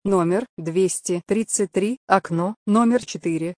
На этой странице собраны звуки электронной очереди — знакомые сигналы, голосовые объявления и фоновый шум.
Женский голос 233, окно 4